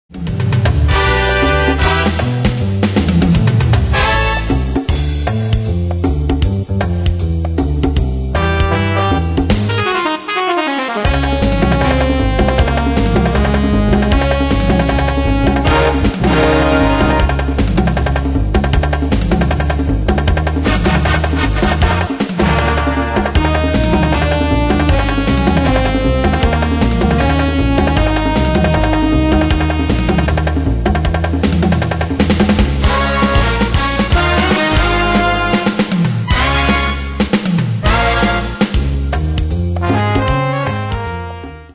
Demos Midi Audio
Style: Retro Année/Year: 1965 Tempo: 156 Durée/Time: 2.09
Pro Backing Tracks